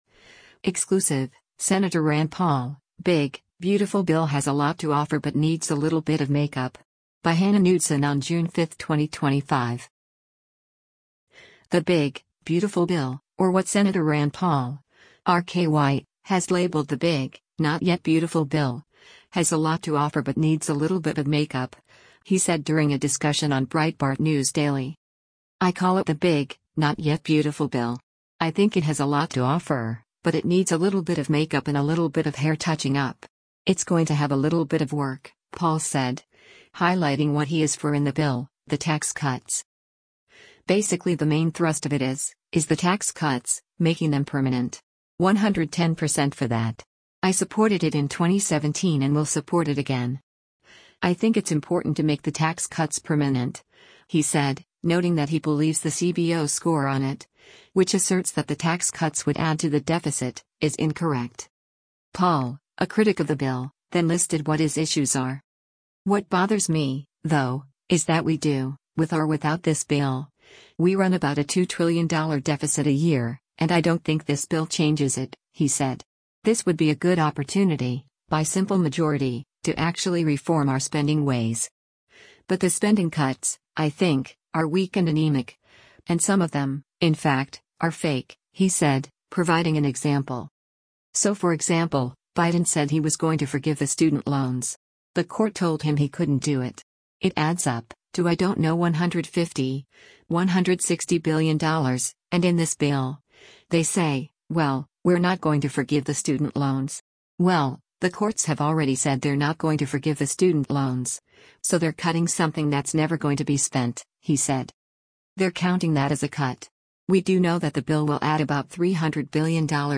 The “big, beautiful bill,” or what Sen Rand Paul (R-KY) has labeled the “big, not yet beautiful bill,” has “a lot to offer” but needs a “little bit of makeup,” he said during a discussion on Breitbart News Daily.